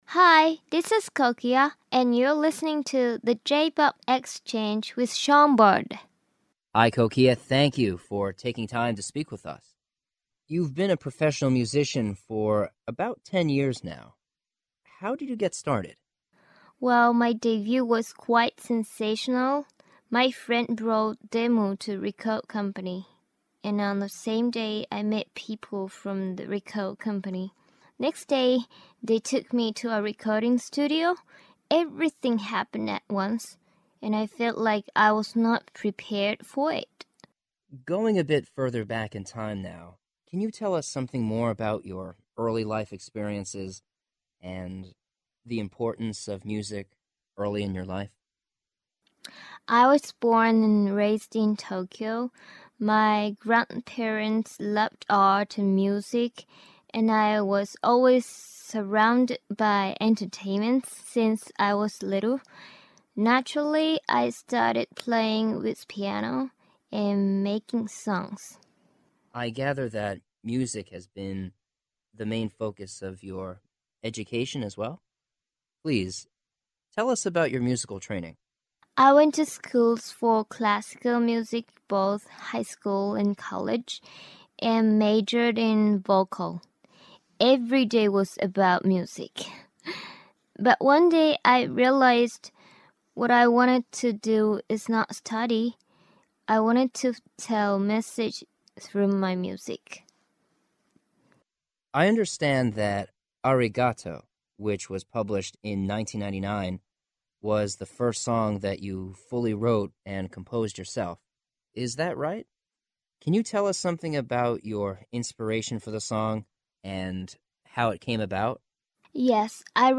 The J-Pop Exchange: Kokia Exclusive Interview
The_JPop_Exchange_Kokia_Exclusive_Interview.mp3